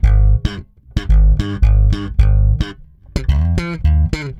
-AL DISCO A.wav